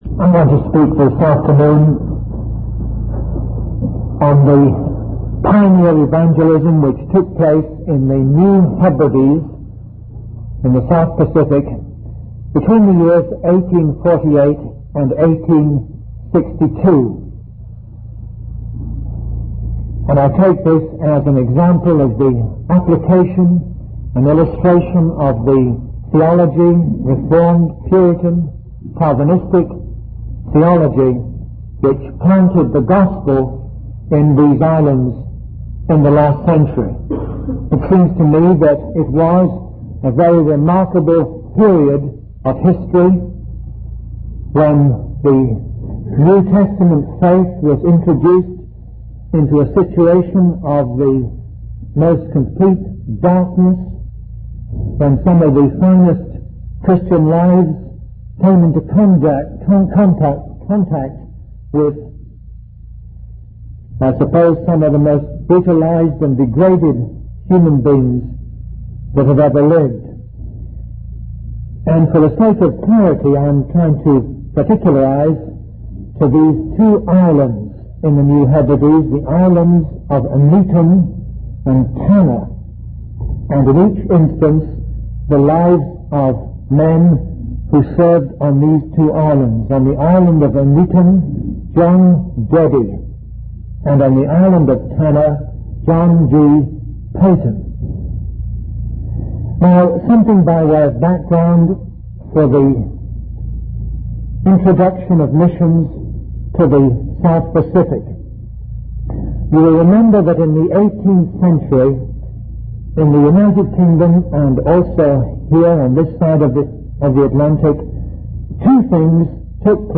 In this sermon, the speaker discusses the powerful impact of the Gospel of Christ and the transformative work of God's grace. He highlights the example of John G. Peyton, a missionary who faced numerous challenges and dangers while preaching and befriending the natives on an island.